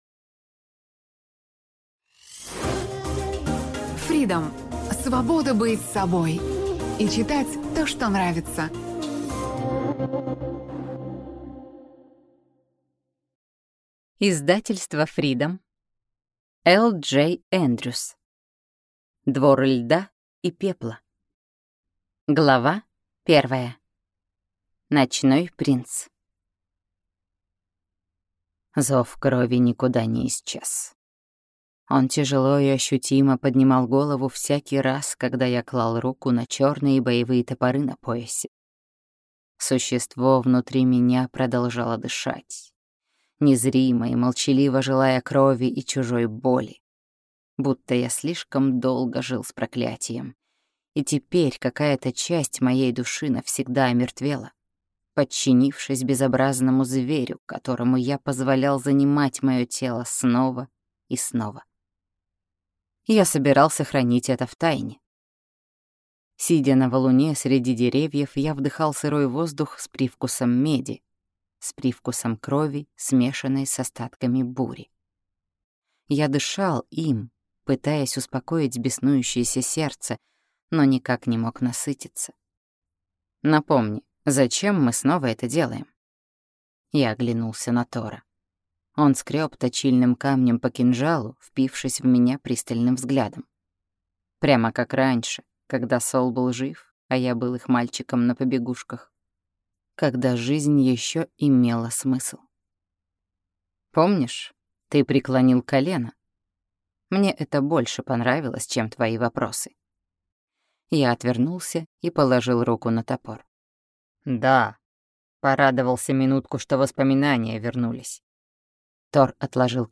Аудиокнига Двор льда и пепла | Библиотека аудиокниг
Прослушать и бесплатно скачать фрагмент аудиокниги